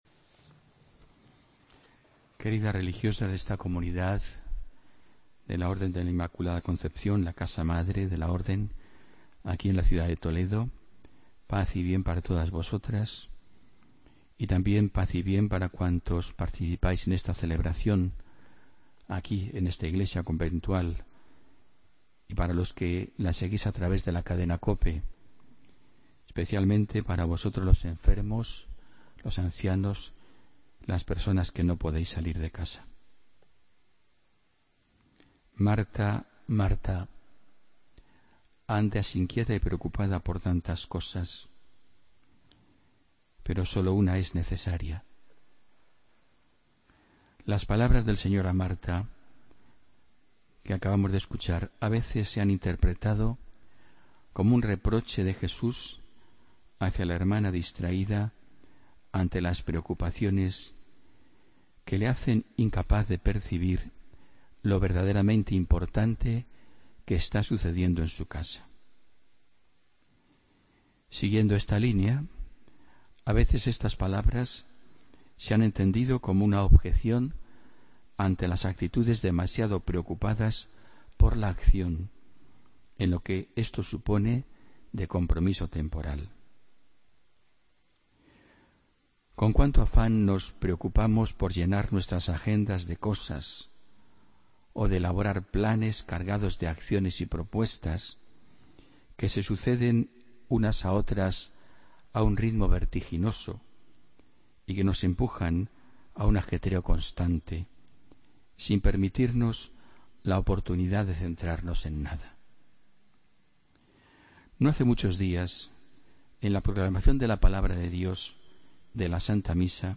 Homilía del domingo 17 de julio de 2016